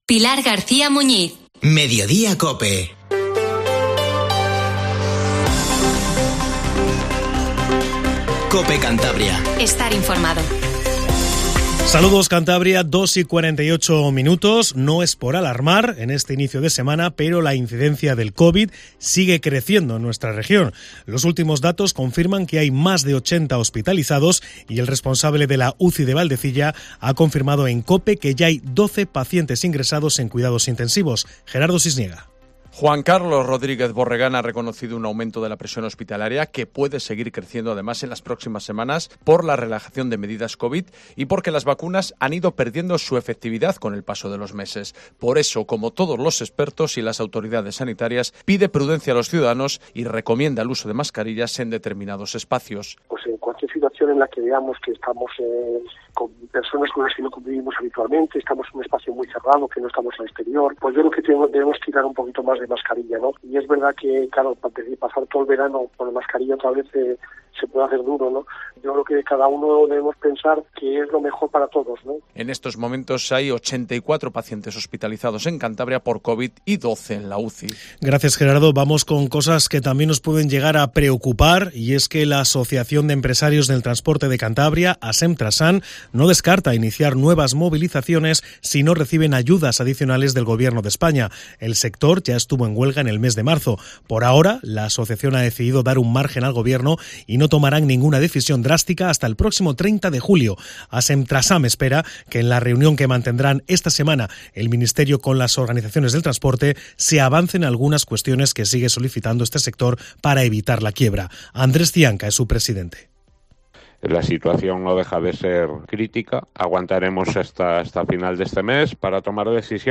Informativo Mediodís COPE CANTABRIA